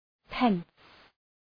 Προφορά
pence.mp3